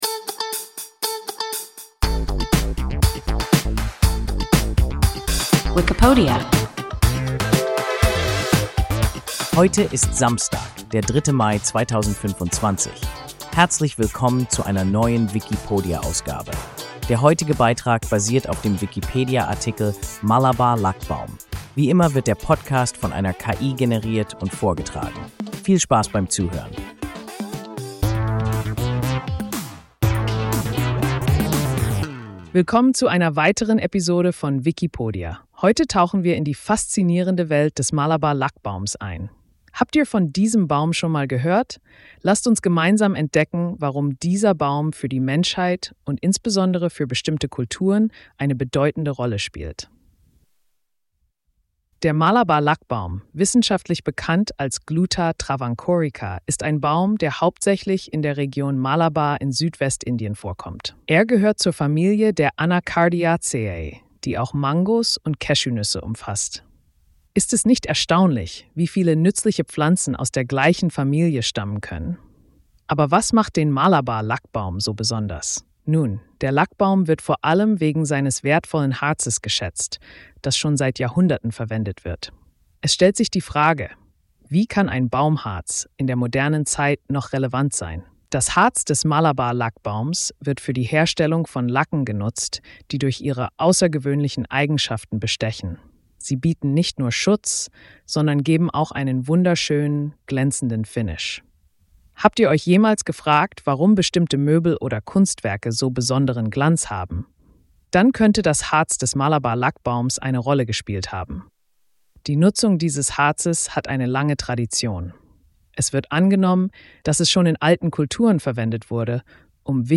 Malabar-Lackbaum – WIKIPODIA – ein KI Podcast